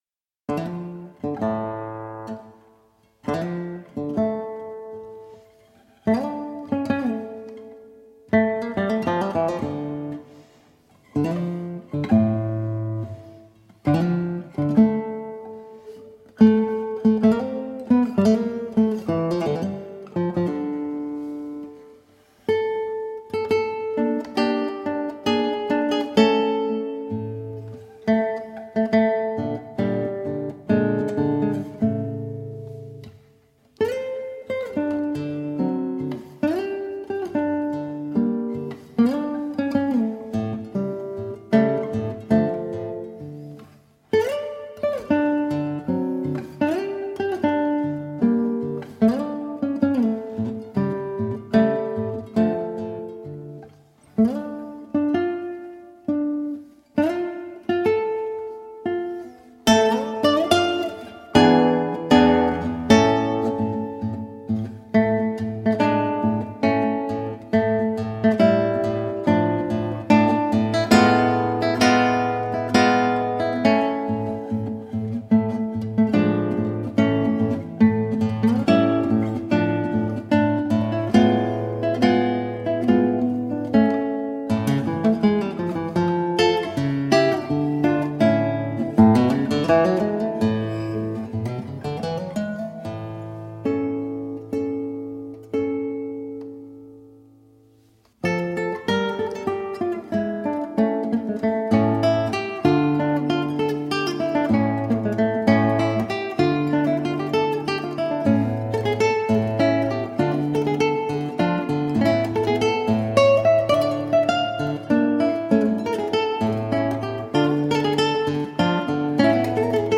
A feast of renaissance and baroque music.
Classical, Renaissance, Instrumental